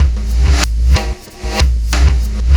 Black Hole Beat 04.wav